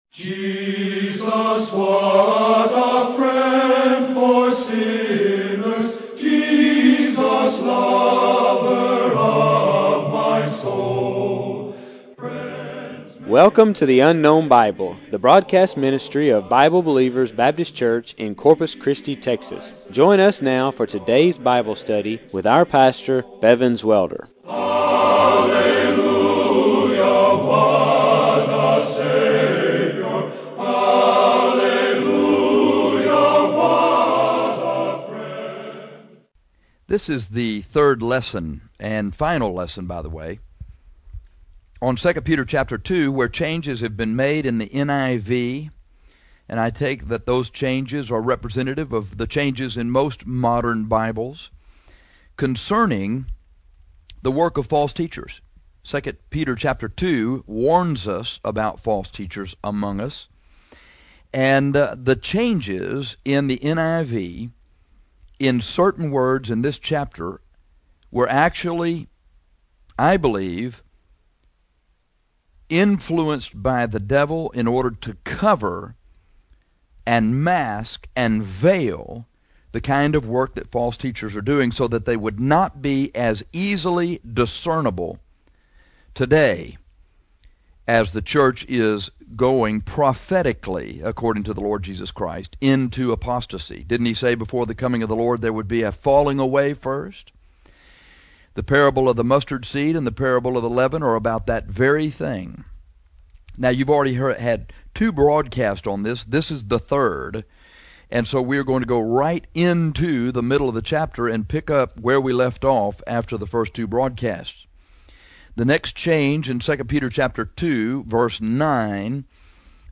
This is the third and final broadcast on the changes to 2 Peter 2 that have been made in the NIV to cover-up the deceitful work of false teachers.